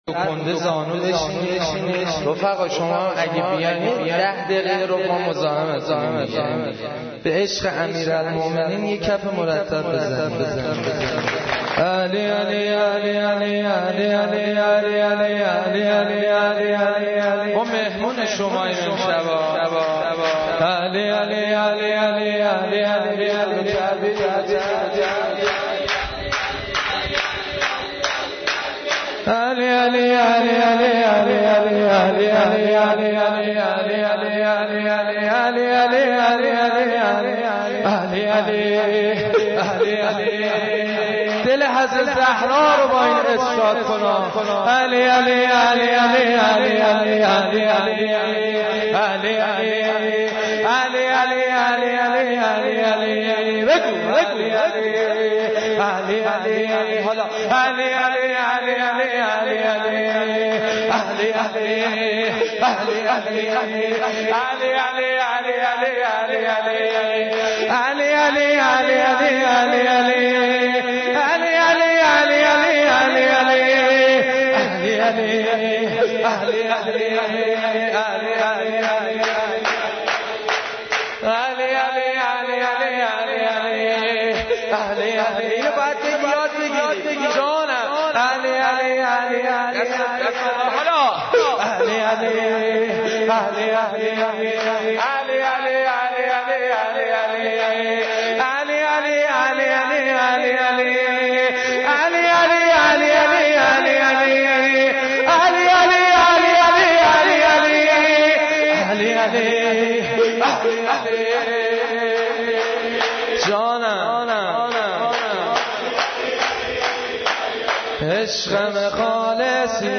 مولودی خوانی